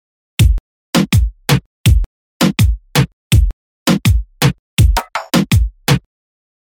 トレシージョ・リズム
↓の音源のようなドッッタドッタッが基本パターンになります。
reggaeton1.mp3